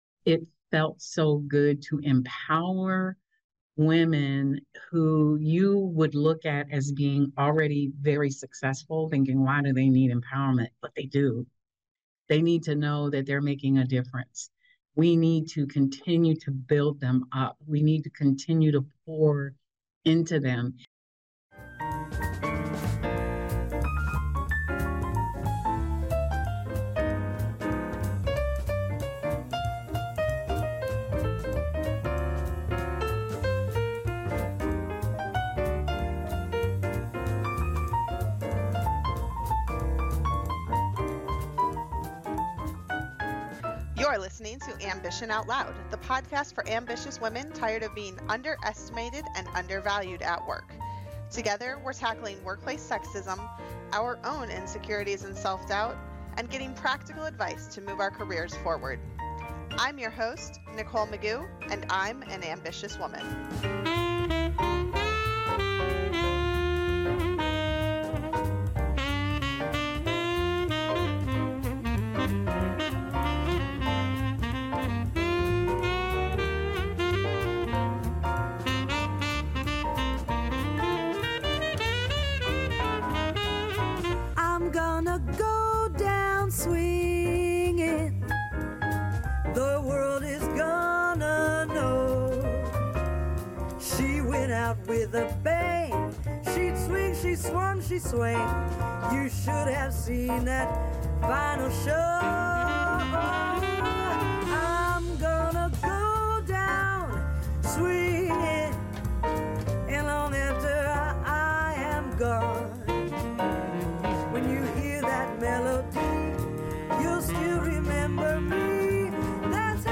In this episode, I sit down with Mayor Michelle Davis-Younger to discuss some of the ways she's leading by empowering others.